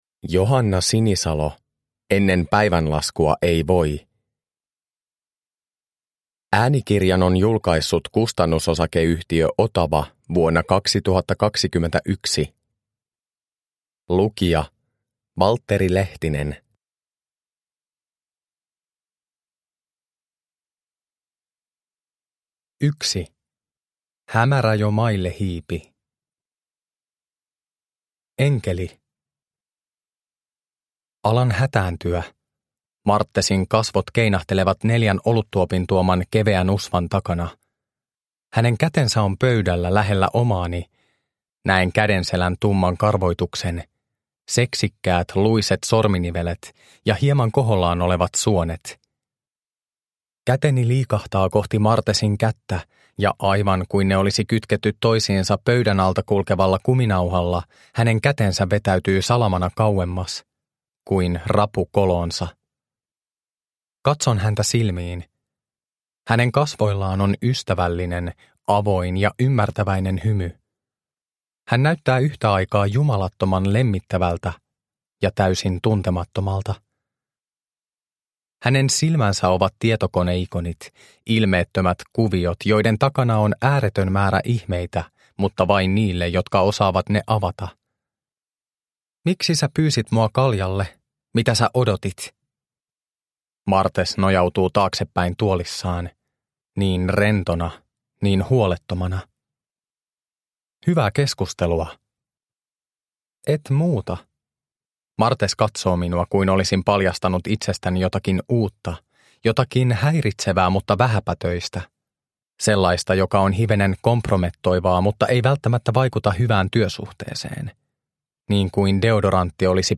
Ennen päivänlaskua ei voi – Ljudbok – Laddas ner